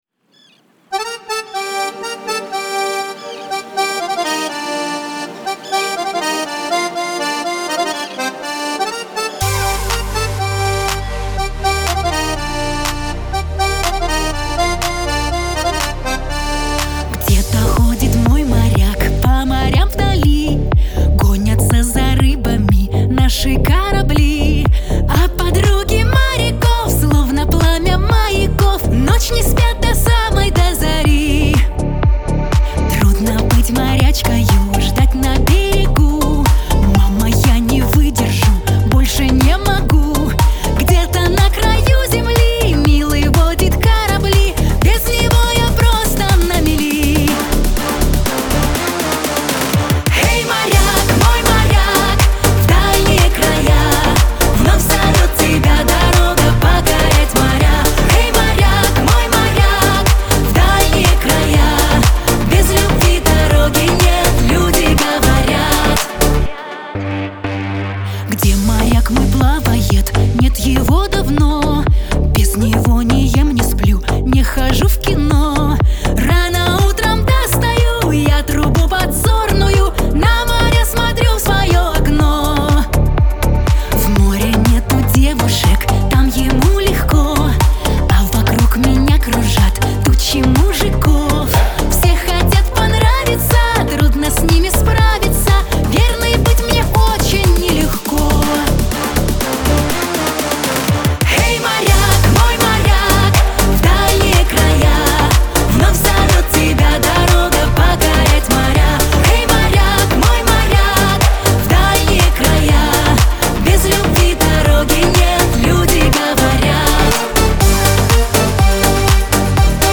эстрада
pop , диско , Кавер-версия